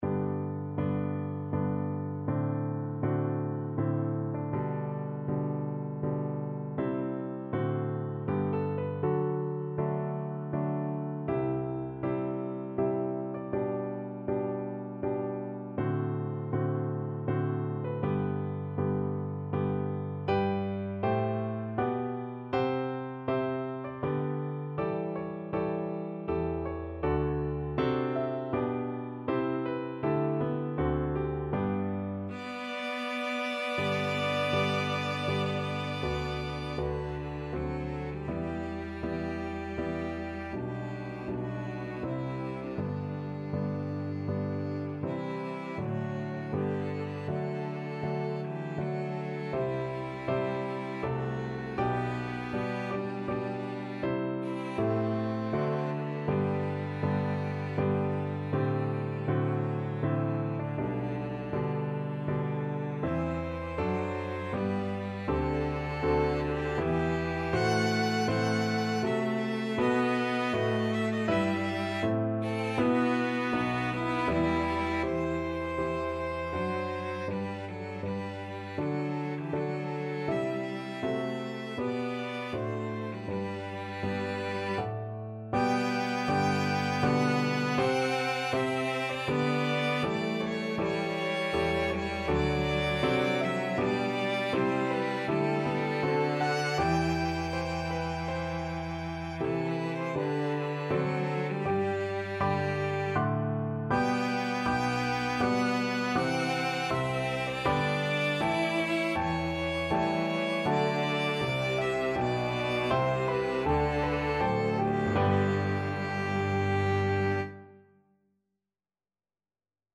Free Sheet music for String trio
ViolinCelloPiano
3/4 (View more 3/4 Music)
G major (Sounding Pitch) (View more G major Music for String trio )
Andante grandioso